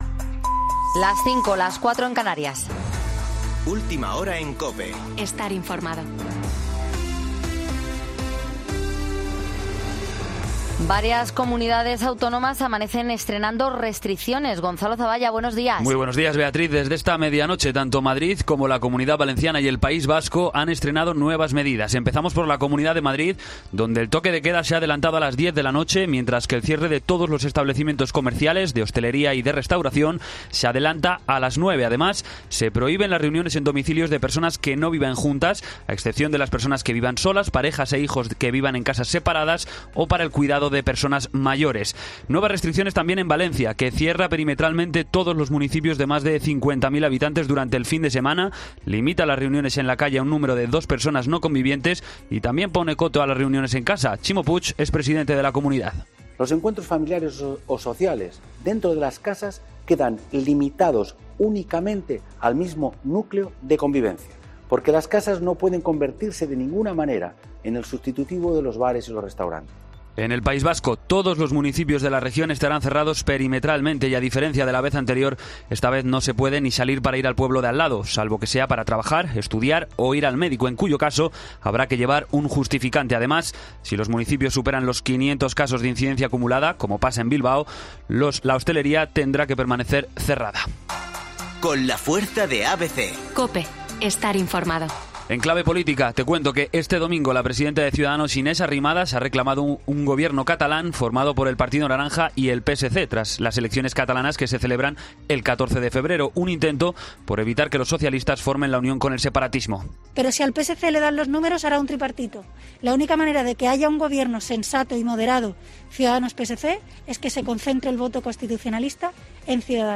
Boletín de noticias COPE del 25 de enero de 2021 a las 05.00 horas